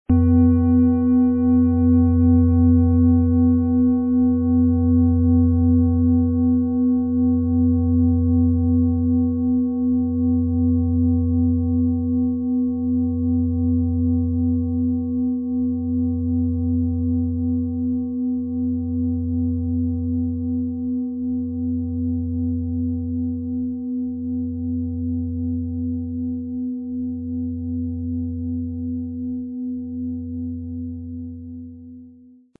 Planetenschale® Lebenslustig sein & Lebendig fühlen mit Delfin-Ton, Ø 25,4 cm, 1600-1700 Gramm inkl. Klöppel
PlanetentonDelphin & Mond (Höchster Ton)
MaterialBronze